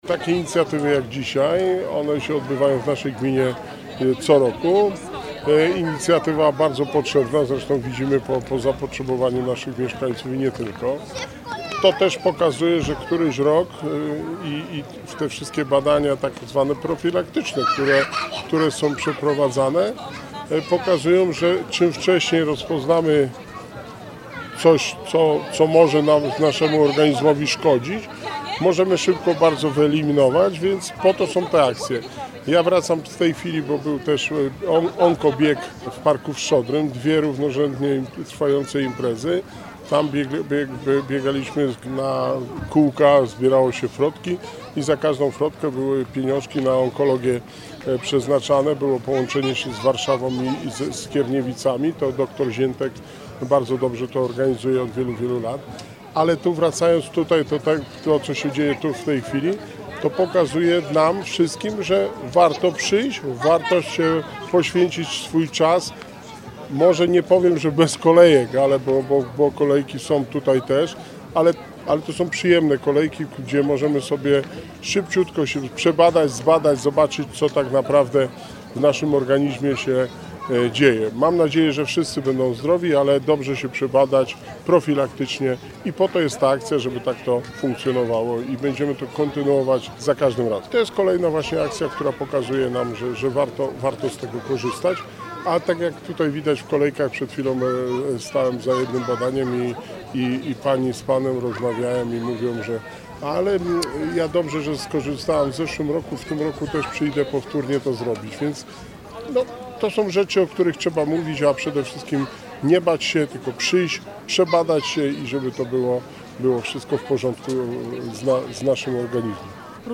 Zdrowa Aktywna Długołęka [relacja z wydarzenia]